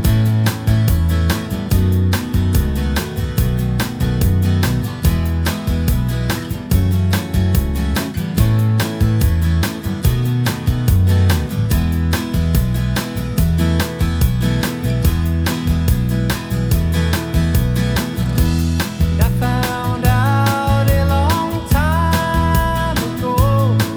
No Guitars Soft Rock 4:13 Buy £1.50